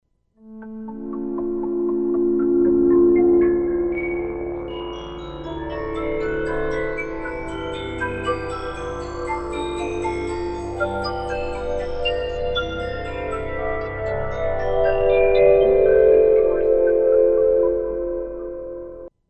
HEAR resonance
Class: Synthesizer